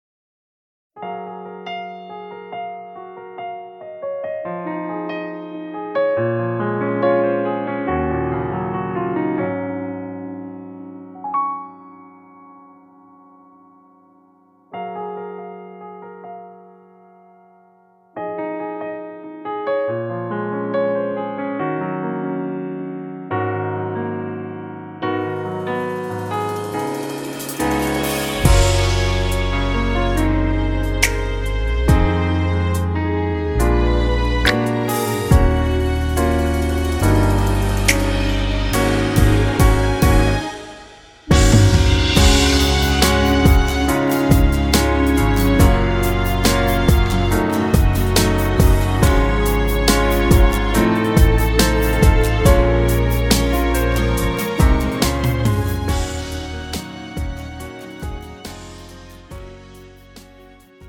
음정 (-1키)
장르 가요 구분 Premium MR